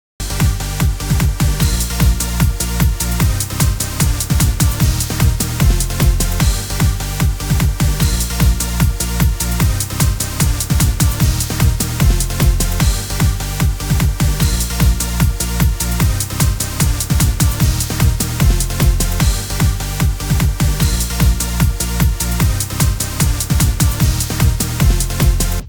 taustamusa